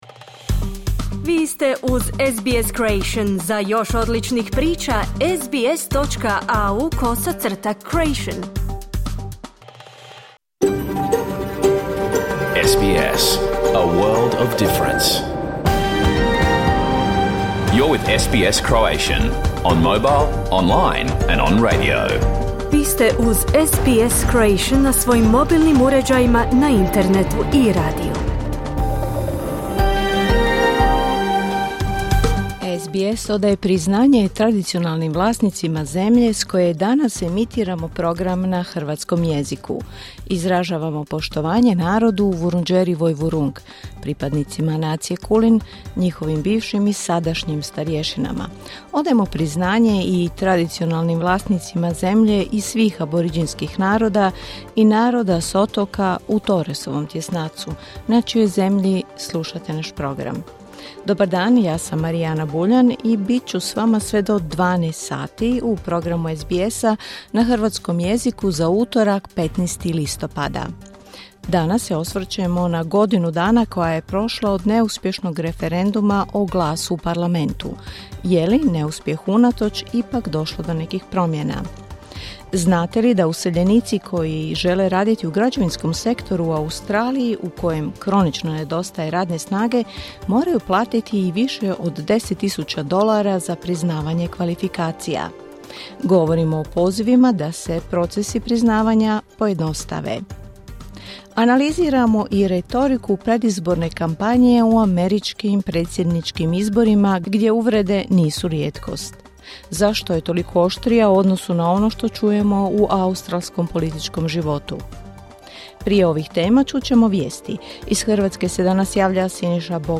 Vijesti i aktualne teme iz Australije, Hrvatske i svijeta u programu koji je emitiran uživo u utorak, 15. listopada, u 11 sati po istočnoaustralskom vremenu.